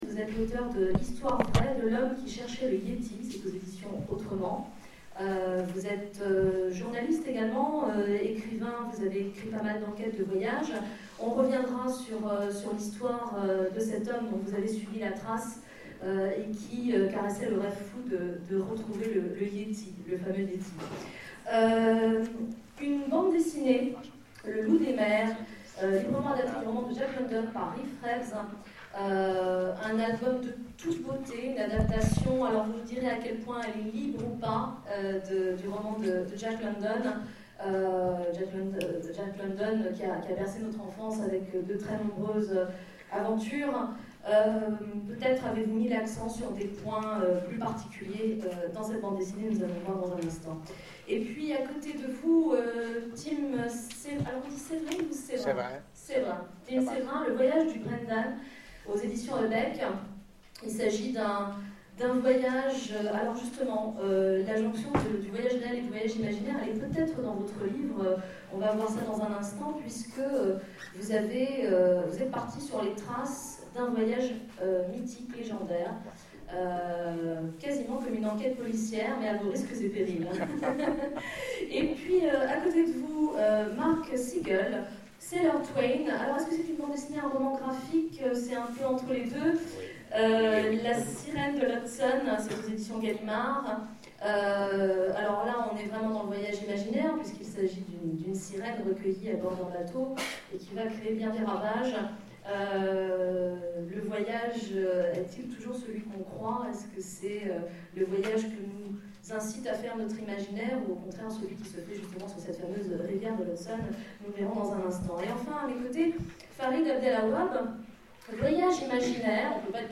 Etonnants Voyageurs 2013 : Conférence Tarzan, l'homme singe
Conférence